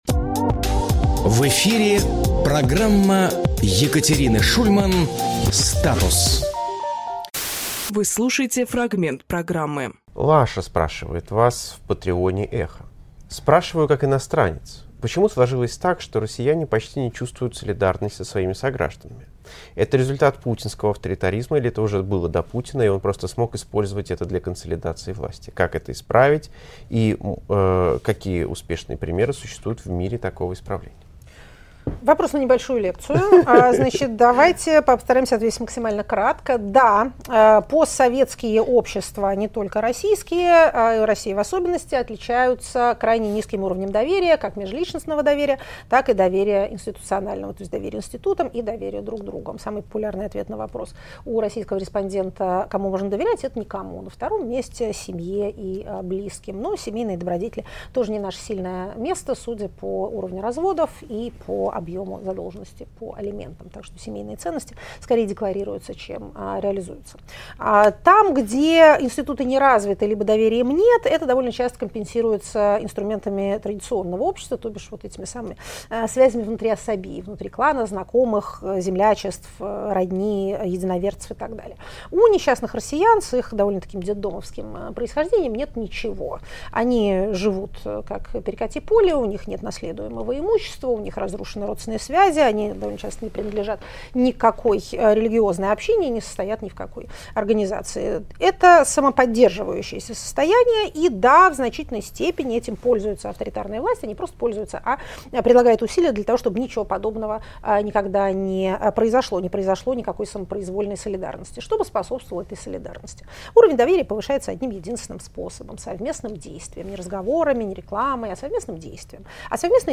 Екатерина Шульманполитолог
Фрагмент эфира от 01.07.25